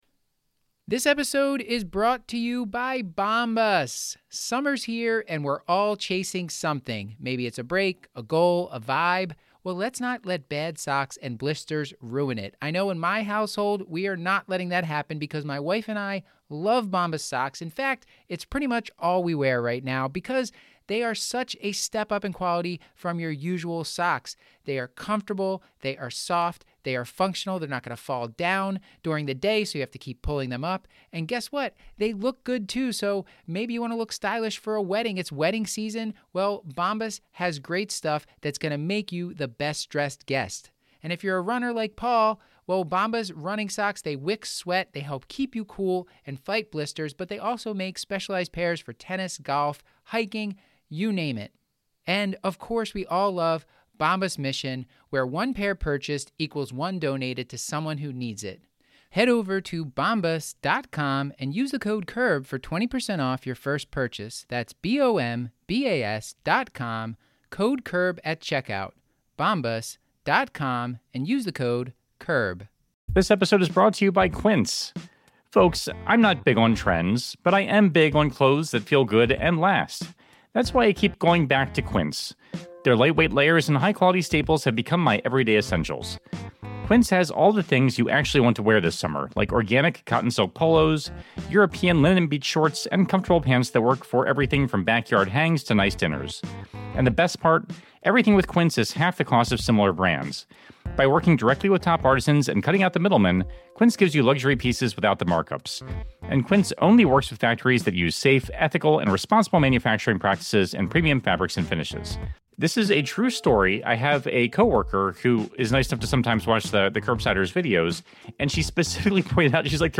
Throw out your down pillows and join The Curbsiders for this lively discussion on interstitial lung disease.